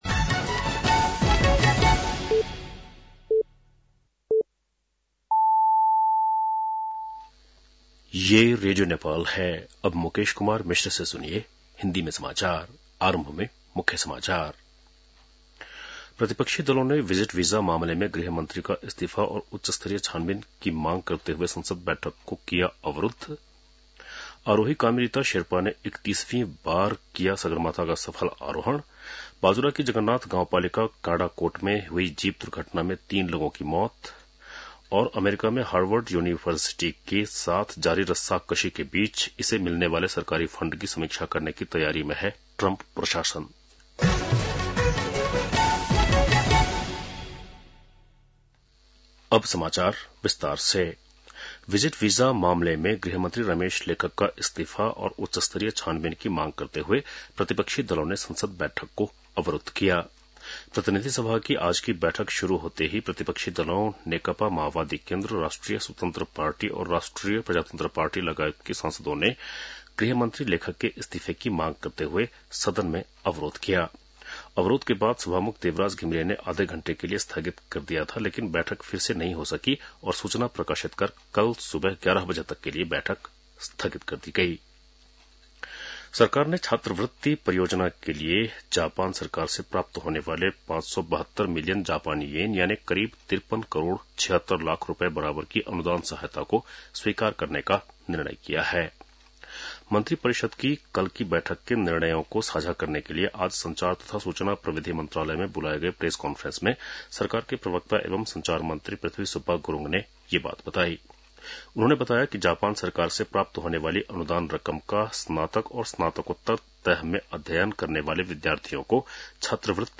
बेलुकी १० बजेको हिन्दी समाचार : १३ जेठ , २०८२